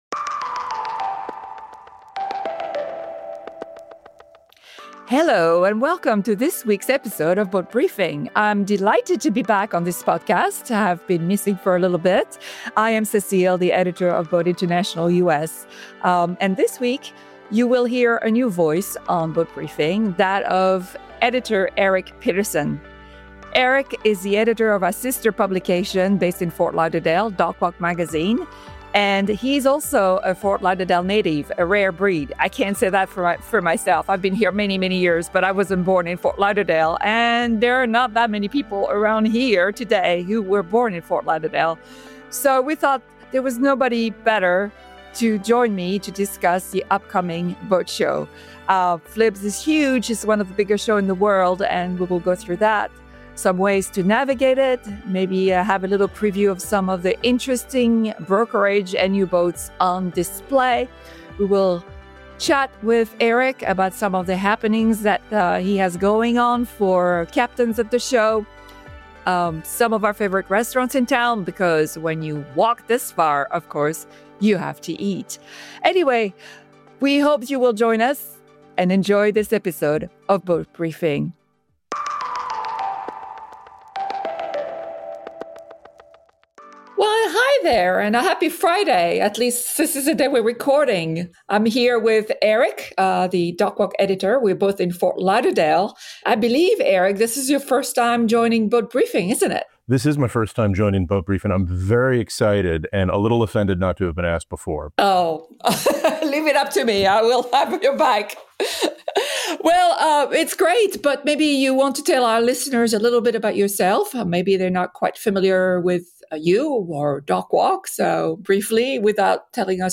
They chat about the scale and impact of this long-running spectacle, which began rather humbly back in 1959, and run through a few of the standout yachts and must-do happenings for captains.